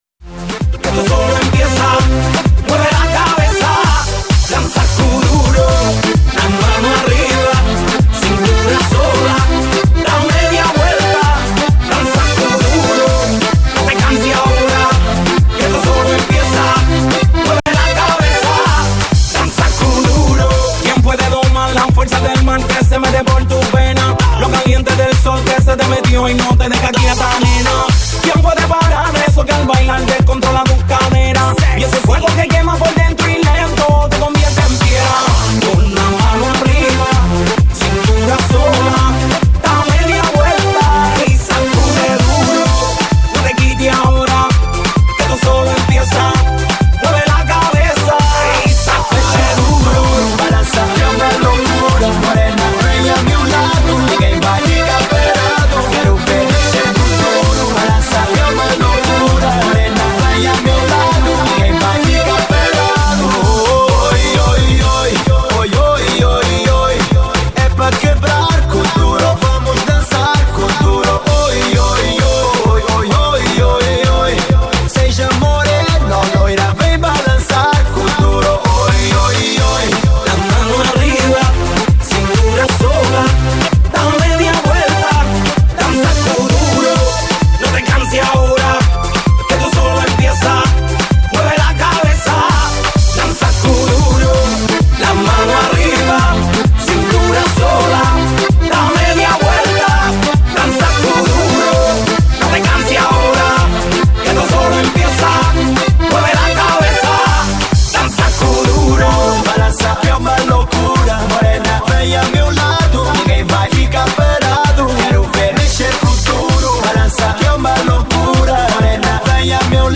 Latin, Urban, Dance, House, Top 40 Broadcasted Live on Woodstock FM
This is what it sounded like inside Nicos Sports Bar & Grill on Saturday November 1 2025